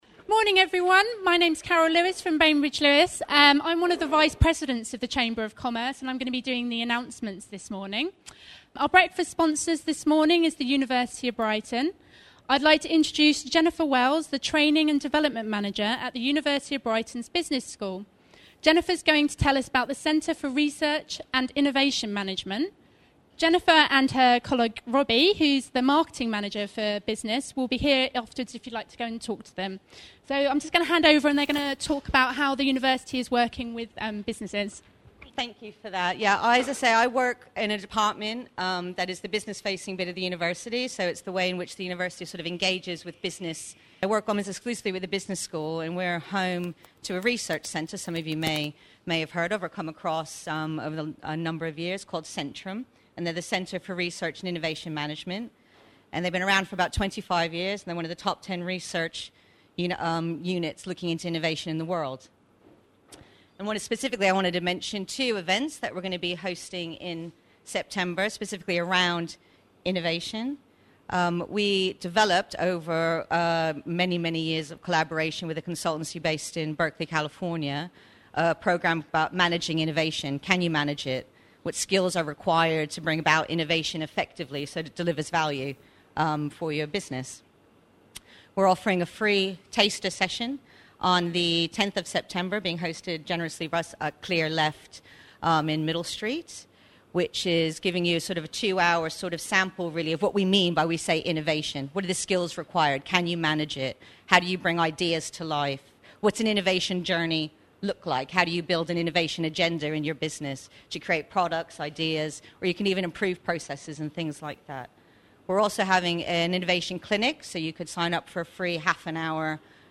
In this series we'll feature a variety of speakers from the Chamber's local business events, including our breakfast talks and Ride the Wave events.